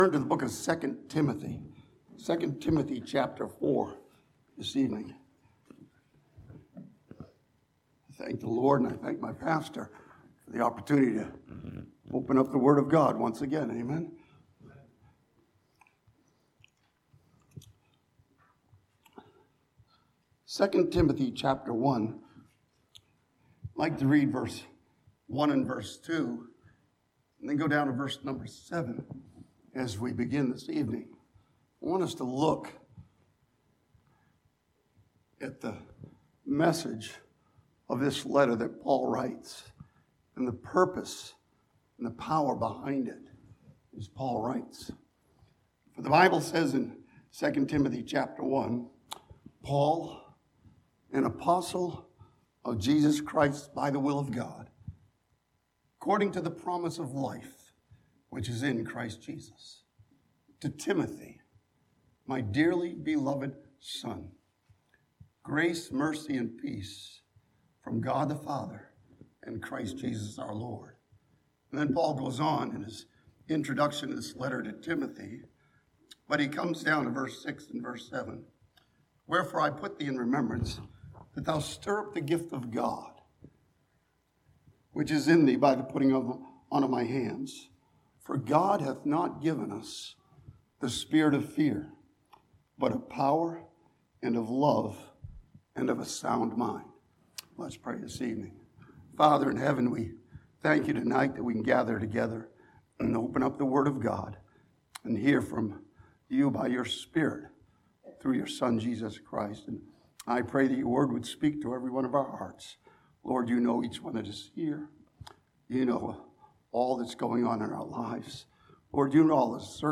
This sermon from 2 Timothy chapter 1 looks at Paul's message to Timothy and the challenges he gives him.